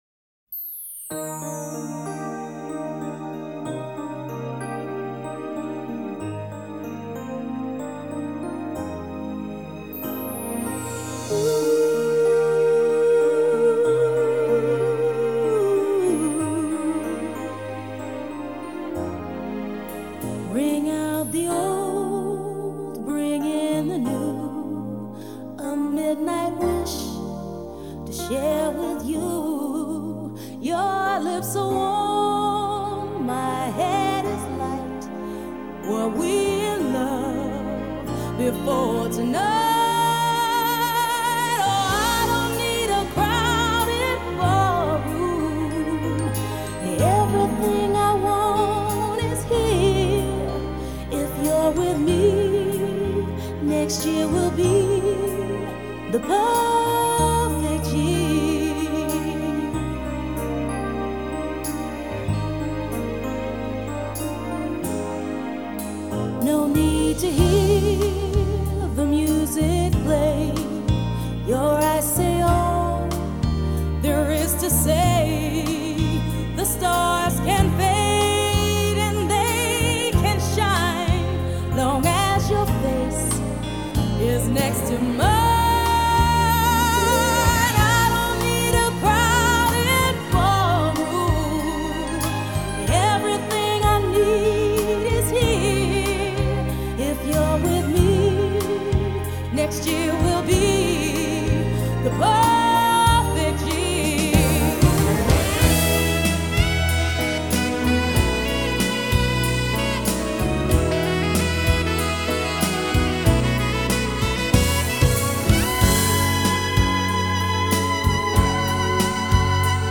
她的声音甜美柔润，清亮而高亢
明朗澄澈的录音效果